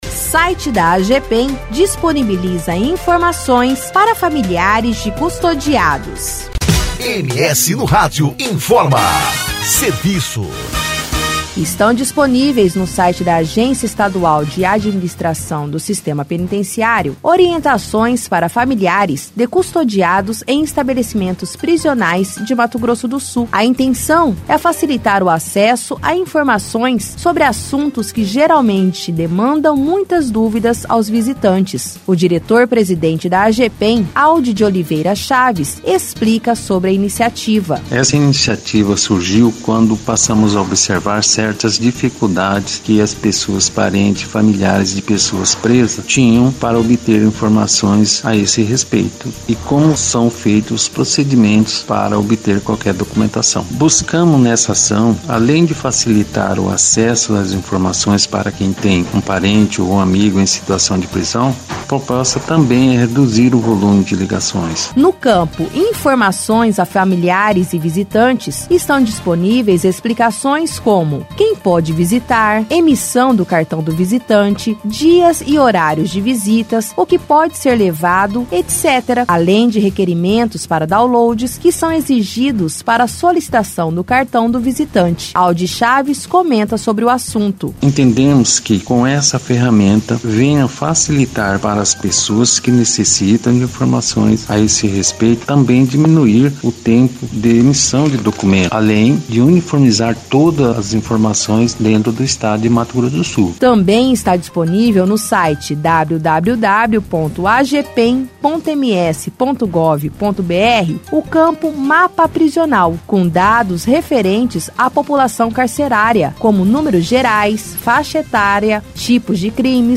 O diretor-presidente da Agepen, Aud de Oliveira Chaves, explica sobre a iniciativa.